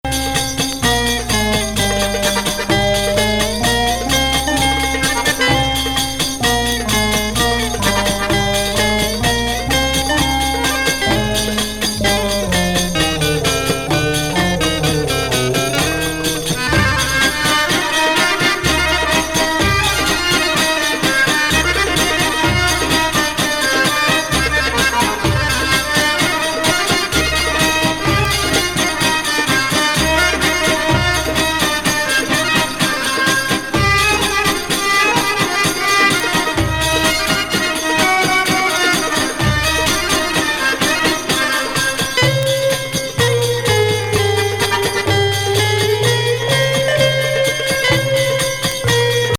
サイケ・エフェクタブルな
アラビック・グルーヴ!